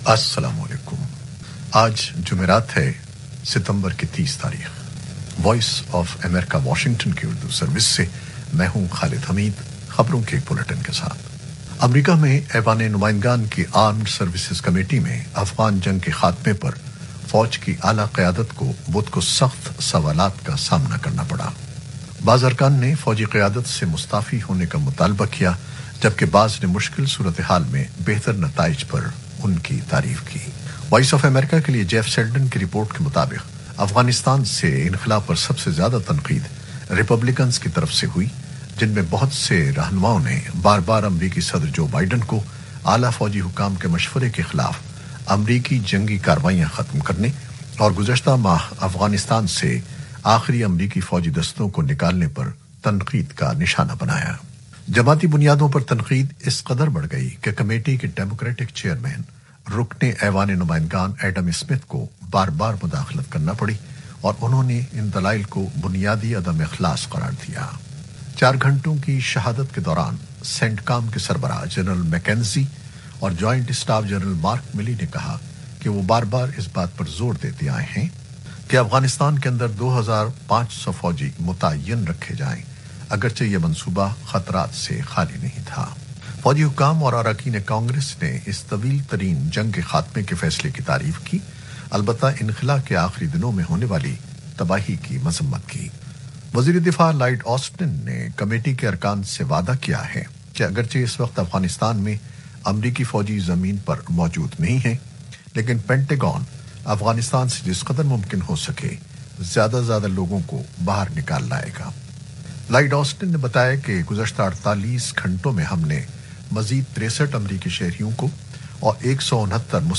نیوز بلیٹن 2021-30-09